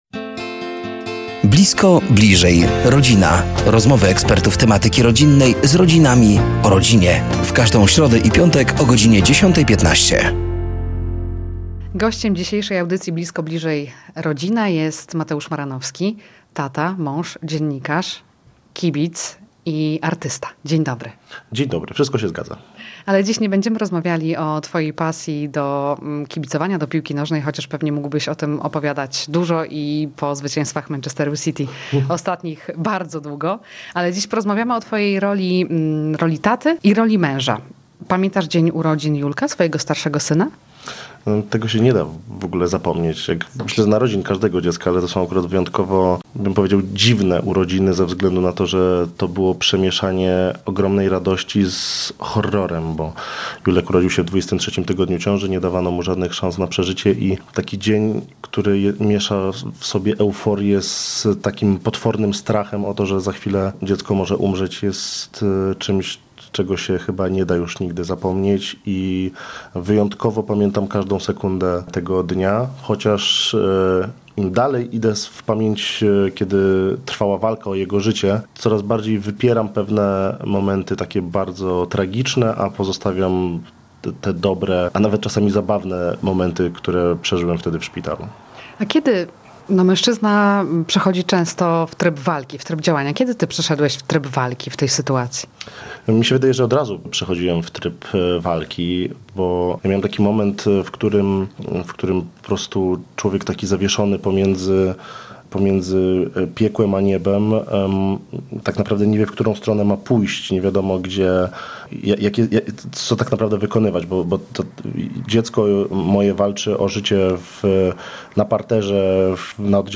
Blisko. Bliżej. Rodzina! To cykl audycji na antenie Radia Nadzieja. Do studia zaproszeni są eksperci w temacie rodziny i rodzicielstwa.